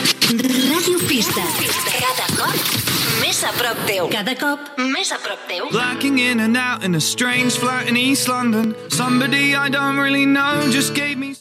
f22a945ef339a2de0a2df20ec0e09f0d0a16b814.mp3 Títol Ràdio Pista Emissora Ràdio Pista Titularitat Pública municipal Descripció Indicatiu de l'emissora i tema musical.